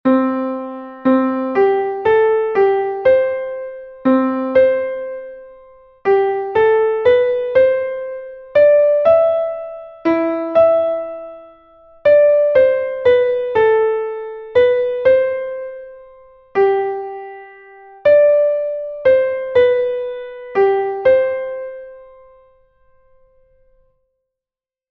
Exercise 2 8ve interval practice